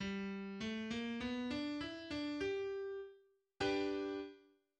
G run in G major variation[1] Play contains both hammer-ons and a pull-off.
In bluegrass and other music, the G run (G-run), or Flatt run[1] (presumably after Lester Flatt), is a stereotypical ending used as a basis for improvisation on the guitar.